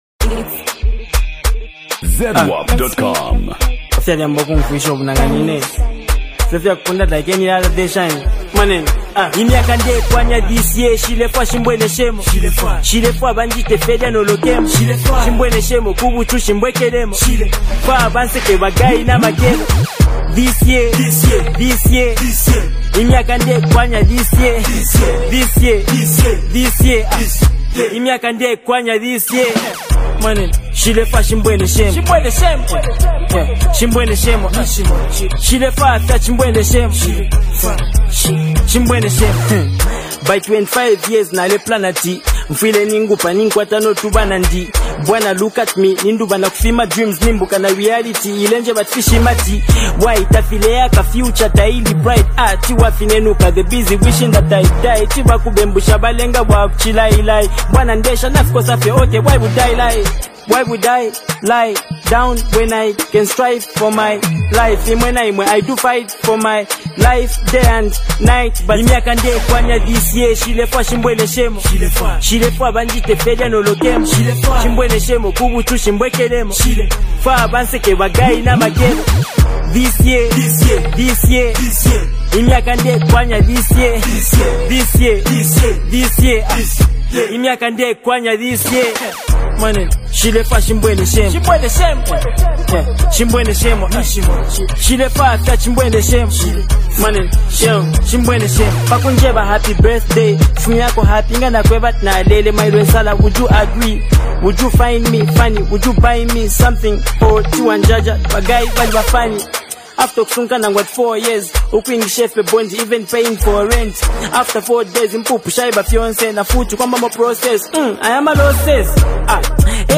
Genre: African Music